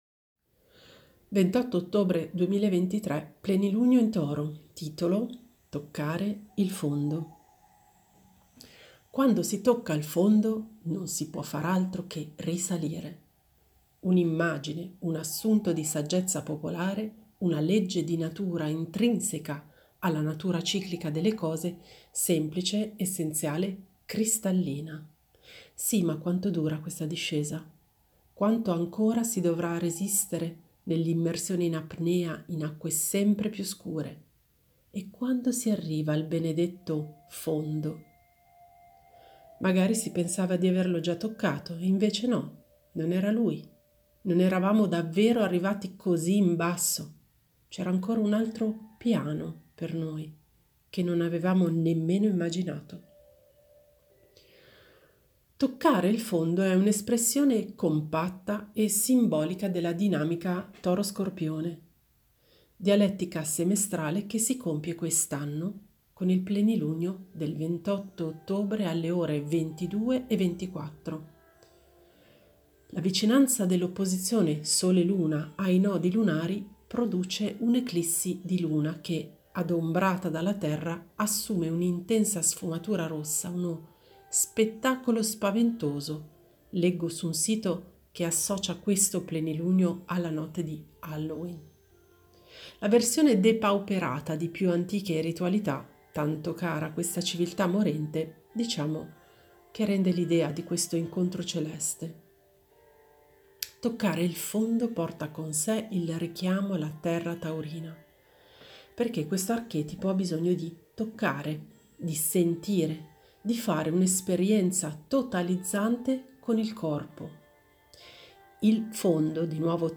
Ascolta l'articolo letto da me